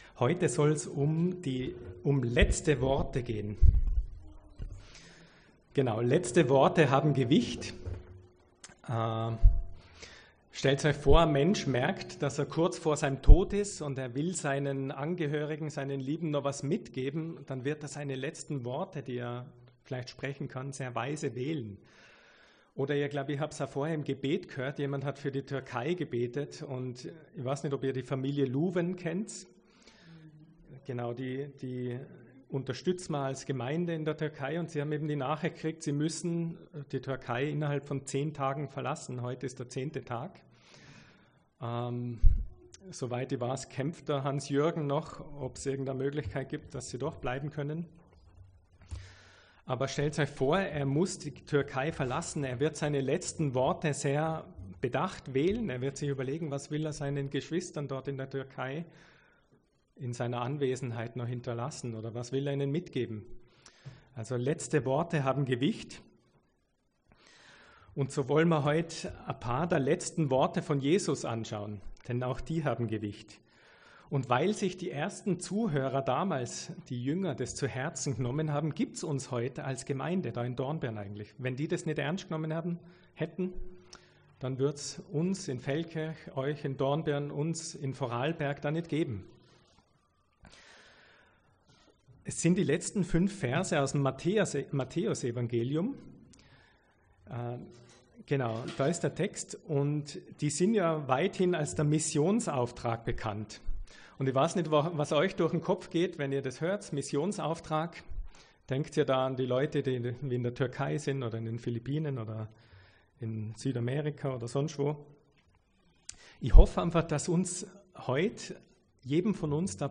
Gastpredigt